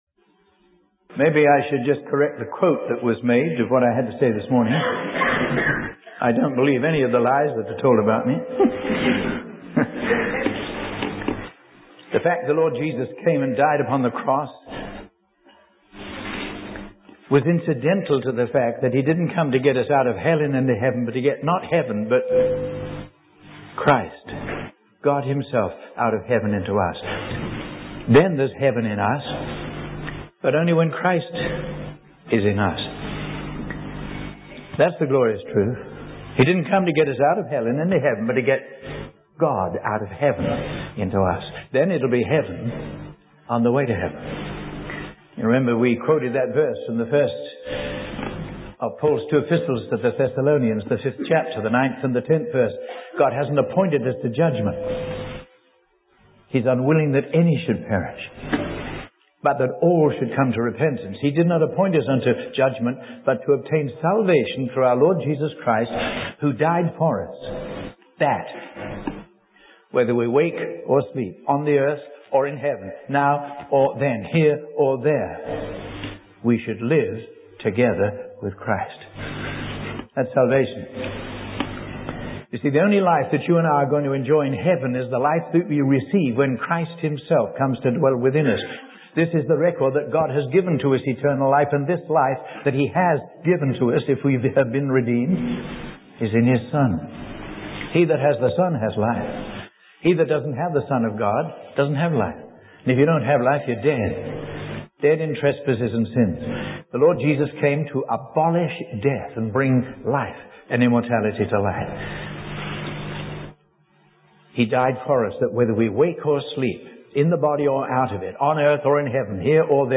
Ultimately, the sermon conveys that the essence of the Gospel is about restoring our relationship with God and living in His glory here and now.